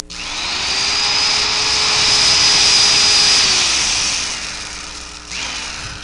Drill Sound Effect
Download a high-quality drill sound effect.
drill.mp3